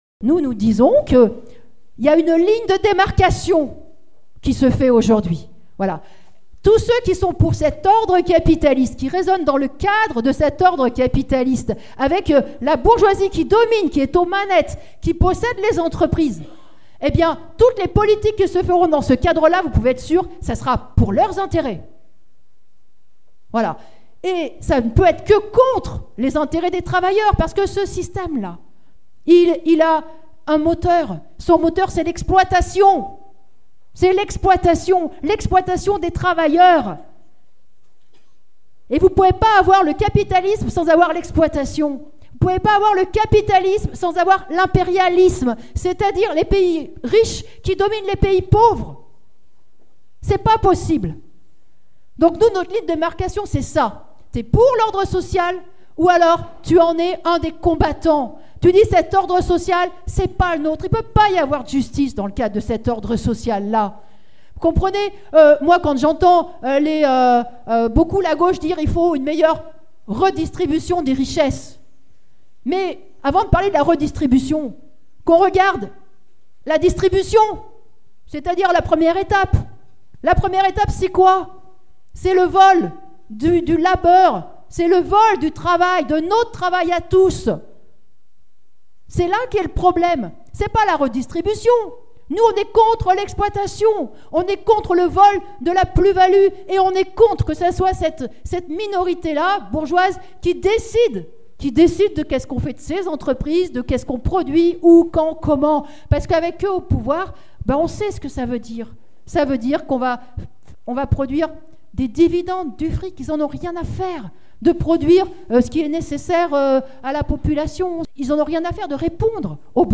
Nathalie Arthaud débat à la fête lyonnaise de LO : La nécessité de combattre le capitalisme